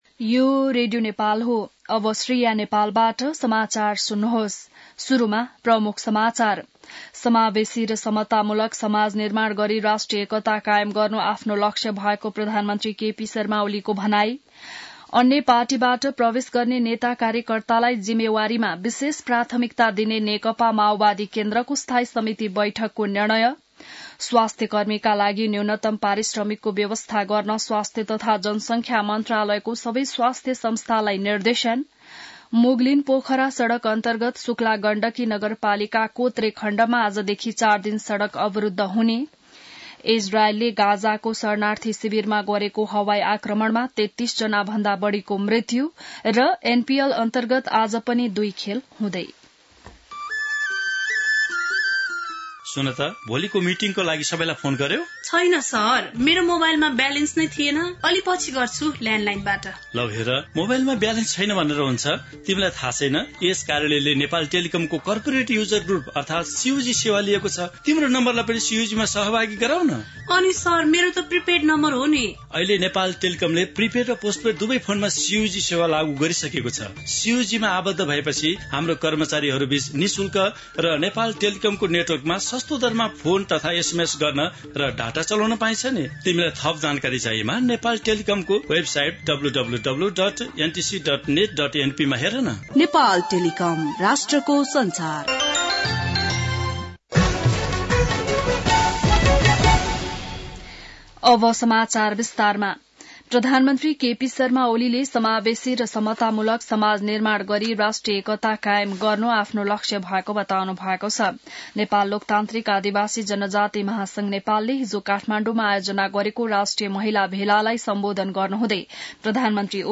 बिहान ७ बजेको नेपाली समाचार : ३० मंसिर , २०८१